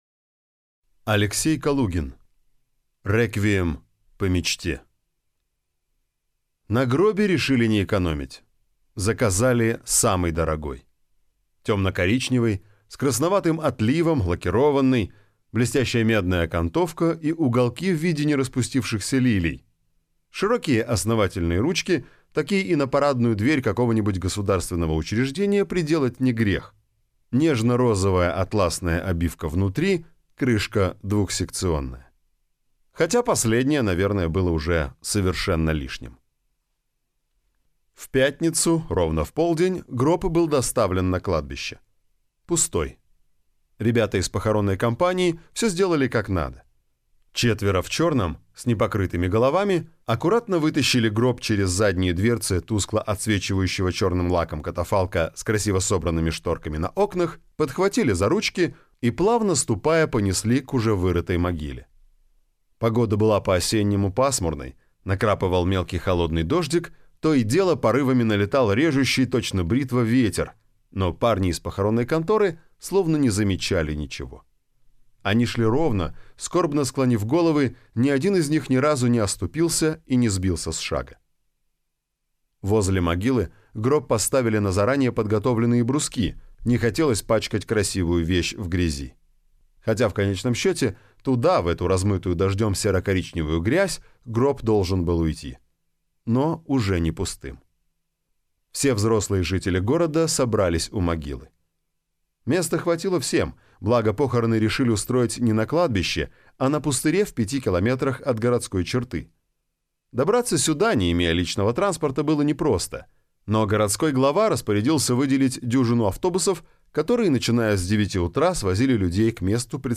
Аудиокнига Реквием по мечте | Библиотека аудиокниг
Прослушать и бесплатно скачать фрагмент аудиокниги